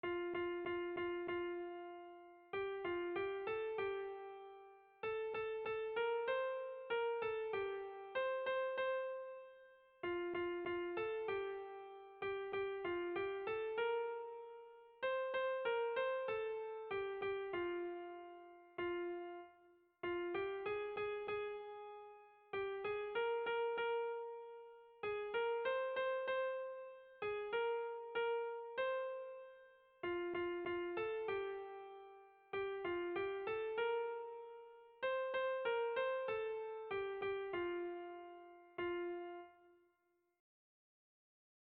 Erlijiozkoa
Zortziko handia (hg) / Lau puntuko handia (ip)
ABDB